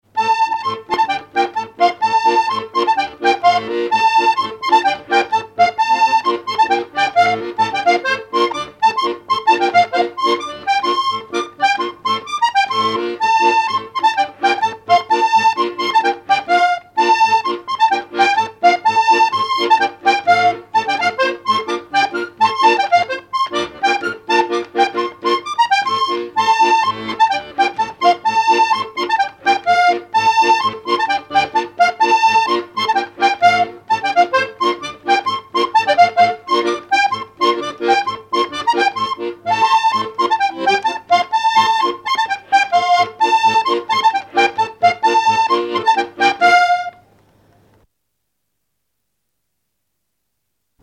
Enregistrement original de l'édition sur disque vinyle
musique pour les assauts de danse et le bal
accordéon(s), accordéoniste ; musique traditionnelle
Pièce musicale inédite